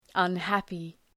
Προφορά
{ʌn’hæpı}
unhappy.mp3